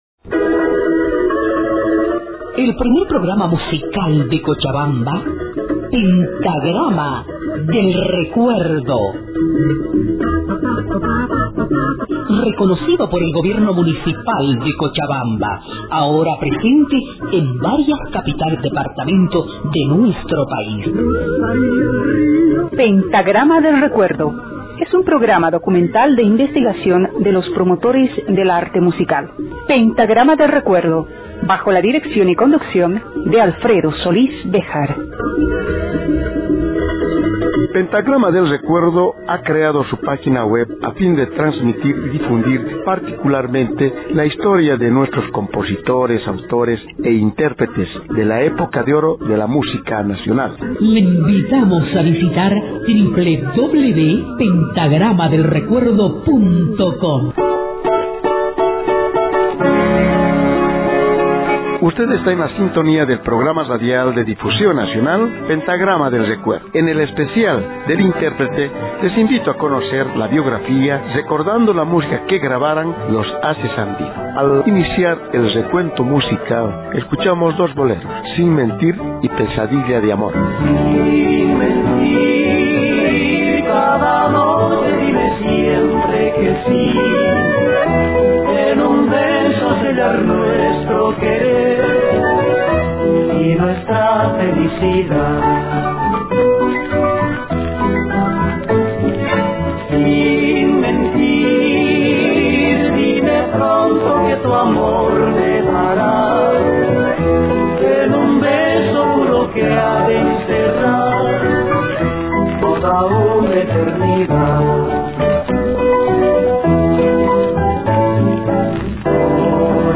piano acordeón
contrabajo